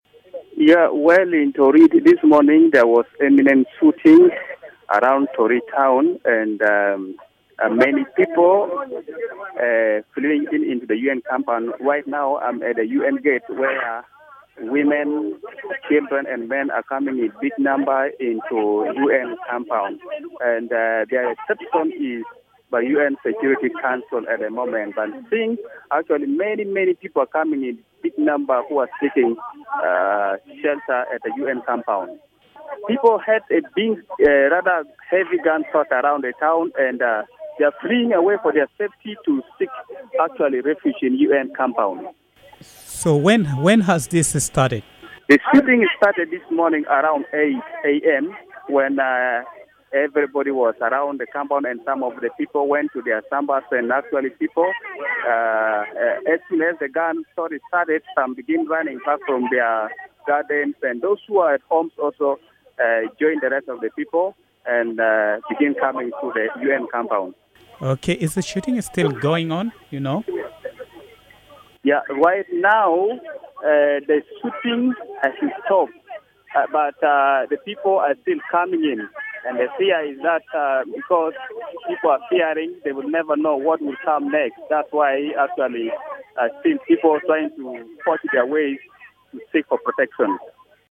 Our reporter in Torit is standing just right outside the UN gate and describes what he has seen.